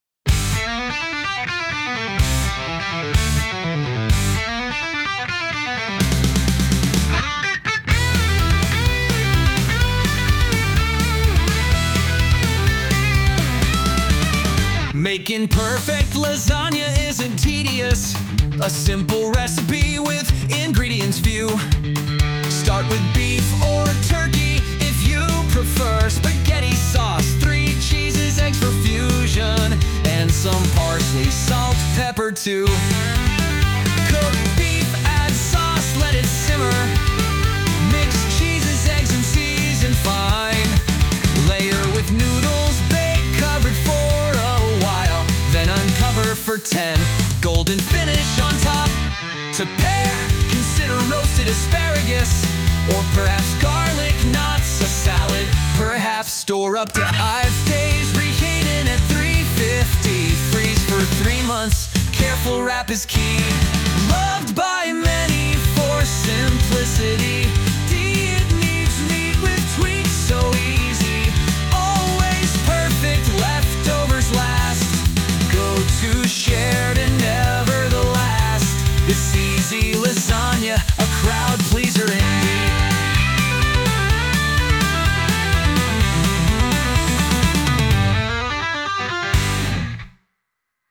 2. Generate the lyrics. We use the OpenAI API to rewrite the content into song lyrics.
Rock song – from an Easy Lasagna recipe